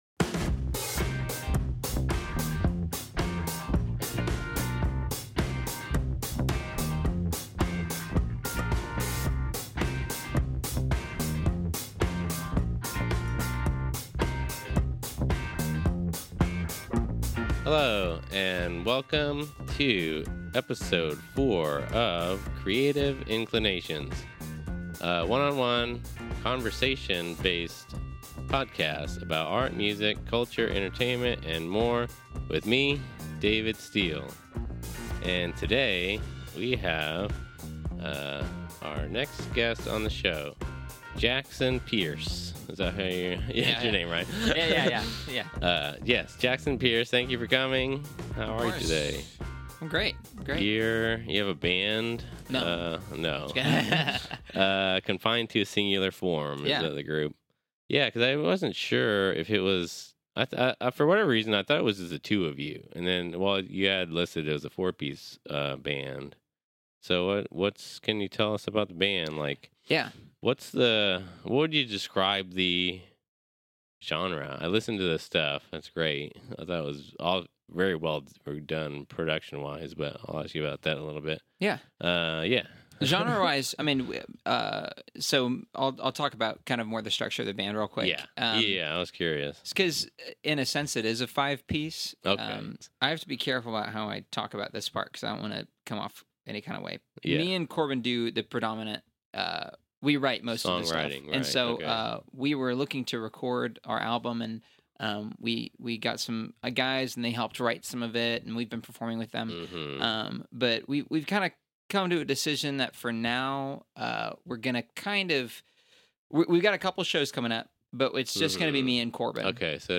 one-on-one interviews